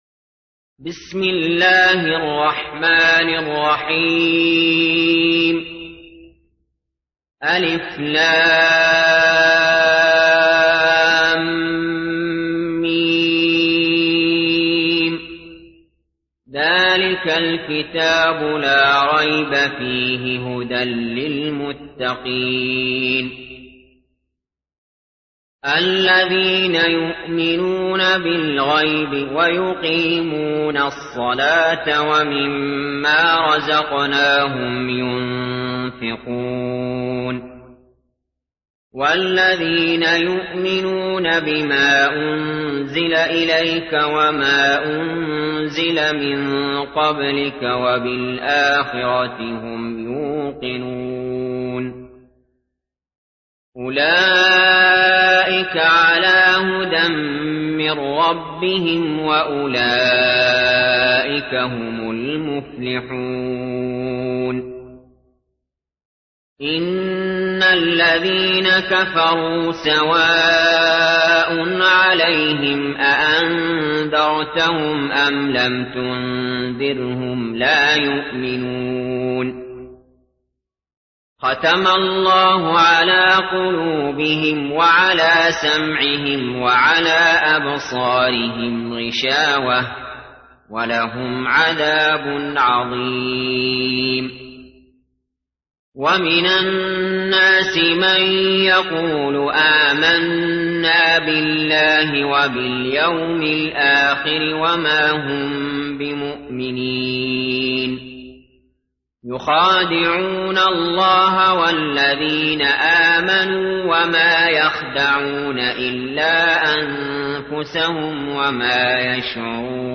تحميل : 2. سورة البقرة / القارئ علي جابر / القرآن الكريم / موقع يا حسين